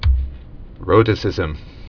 (rōtĭ-sĭzəm)